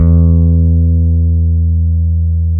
Index of /90_sSampleCDs/AKAI S-Series CD-ROM Sound Library VOL-7/JAZZY GUITAR
JAZZ GT1E1.wav